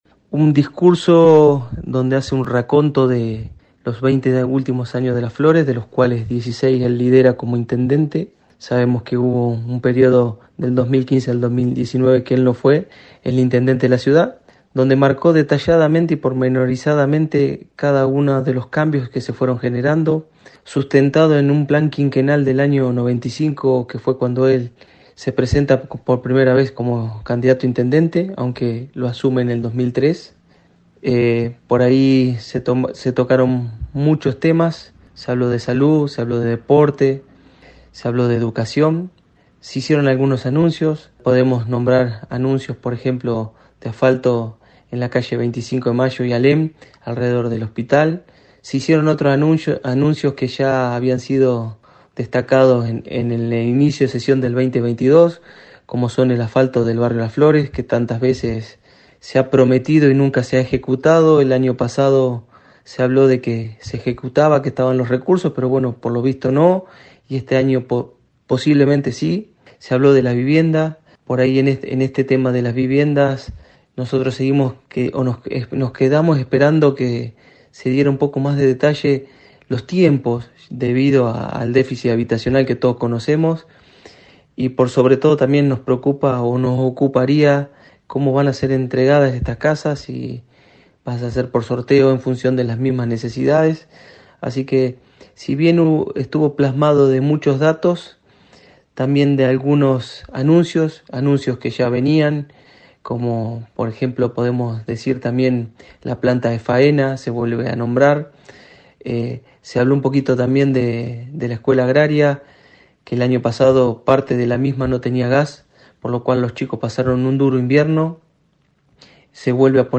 Las voces de los concejales de distintos bloques del Concejo Deliberante:
Concejal por Adelante – Juntos Federico Dorronsoro:“Nos preocupa cómo serán entregadas las viviendas que se están construyendo”